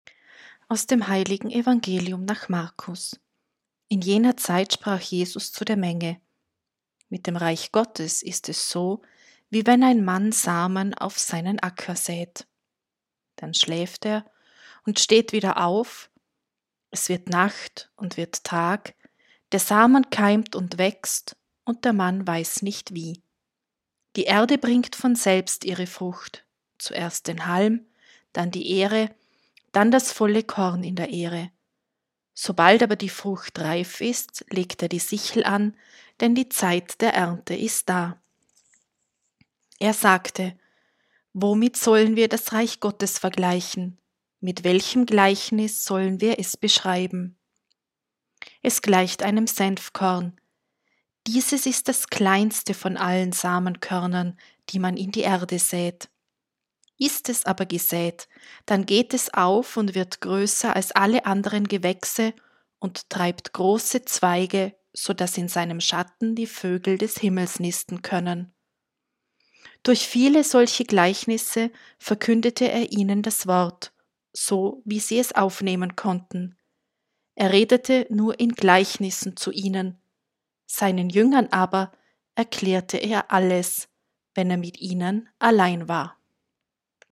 Wenn Sie den Text der 2. Lesung aus dem zweiten Brief des Apostels Paulus an die Gemeinde in Korinth anhören möchten: